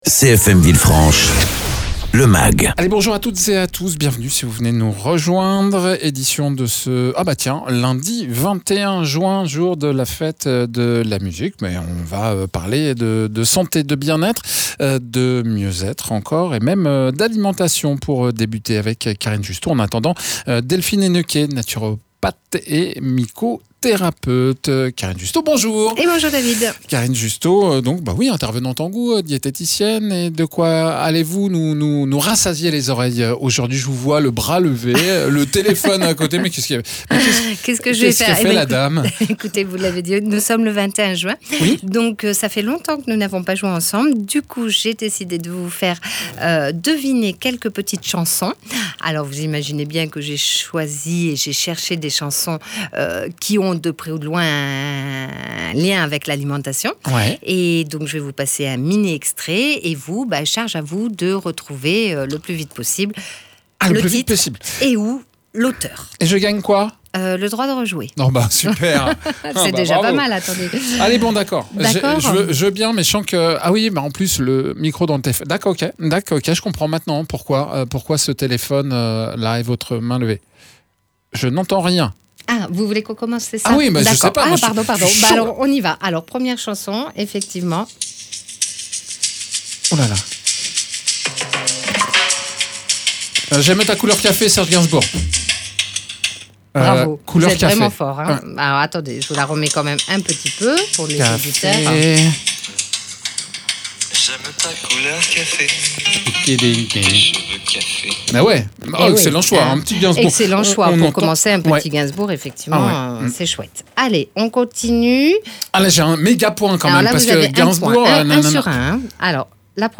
A l’occasion de la fête de la musique, petit jeu avec des chansons qui parlent de nourriture. Egalement dans ce mag les maladies neurodégénératives